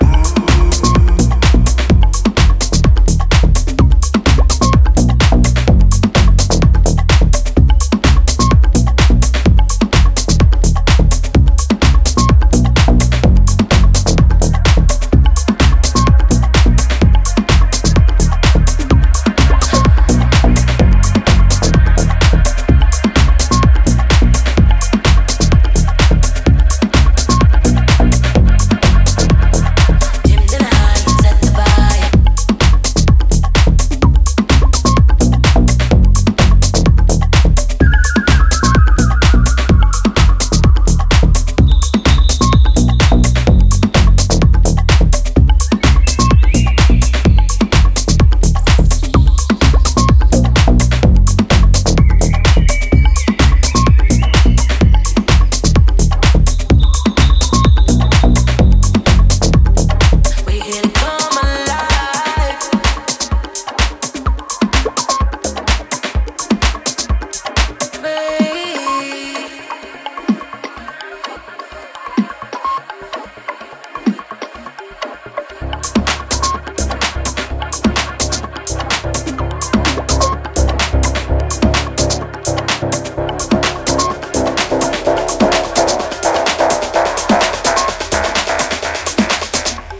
signature low-end punch